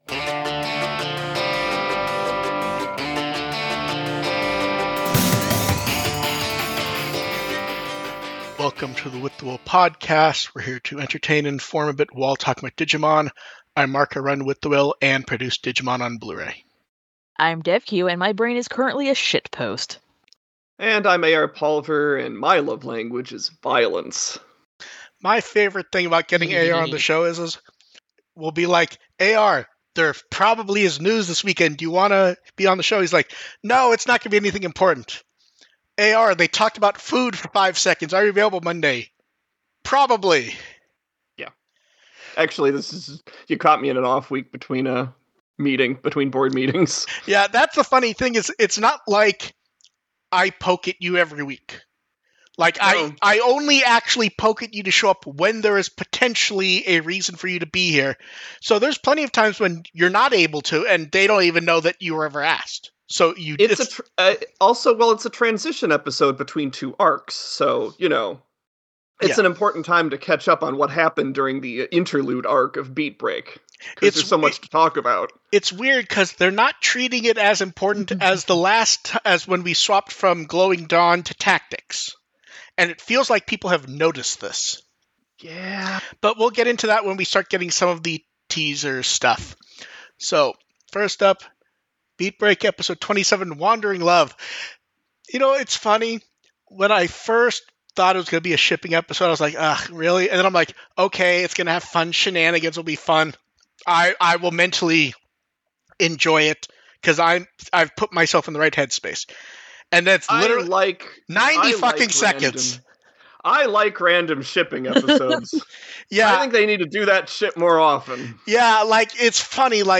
The podcast audio is the livestream clipped out, with an intro and outtro added, along with some tweaking to try and improve audio quality.